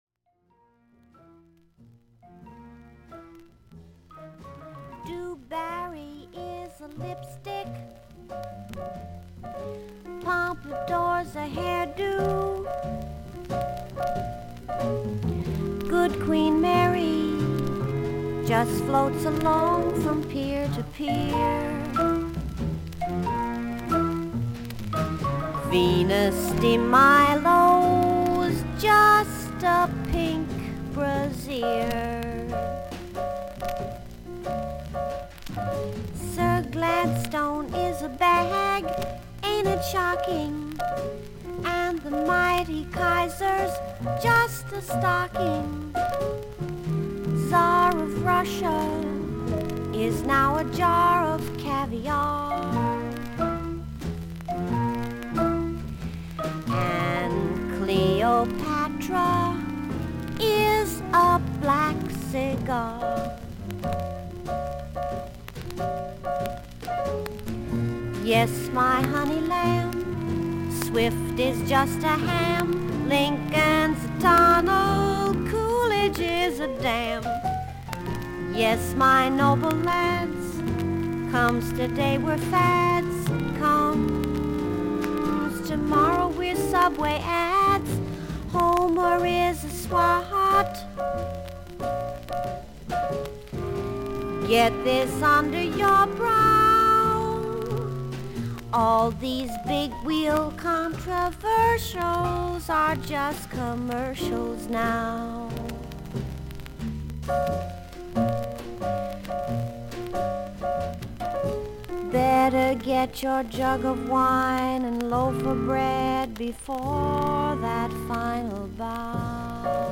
音のグレードはVG+:所々に少々軽いパチノイズの箇所あり。少々サーフィス・ノイズあり。クリアな音です。
独特の可憐な声のジャズ・シンガー/ピアニスト。